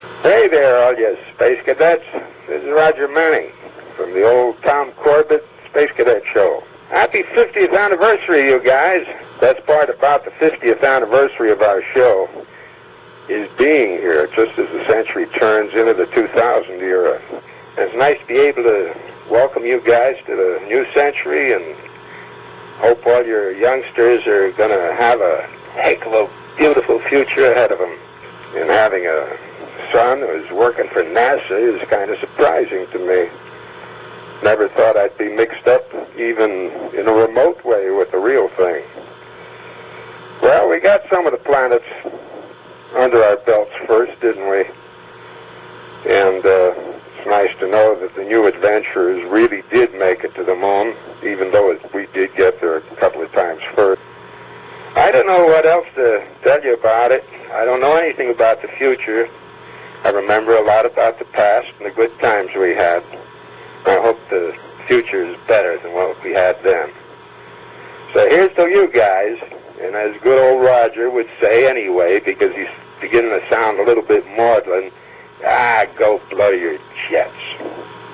The following sound clips are the longer greetings from our favorite Polaris crew members.